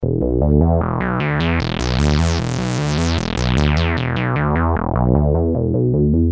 Free MP3 vintage Sequential circuits Pro-1 loops & sound effects 1